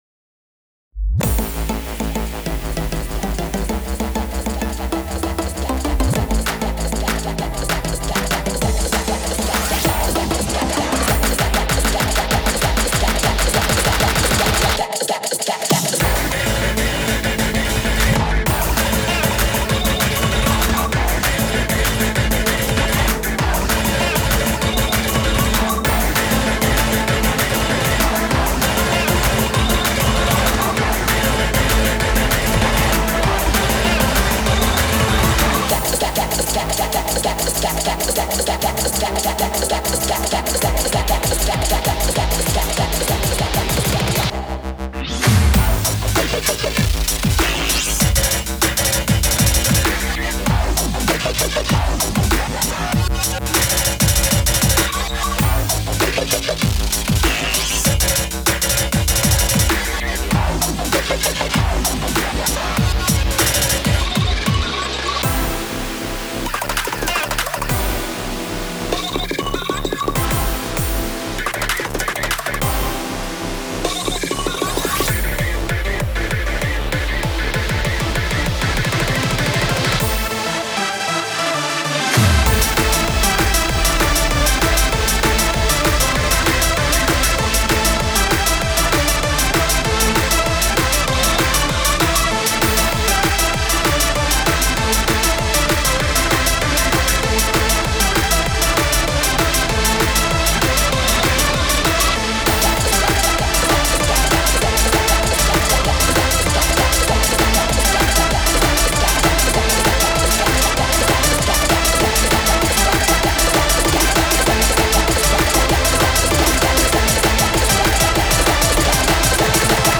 BPM195
Audio QualityPerfect (High Quality)
Yes, this is rushed.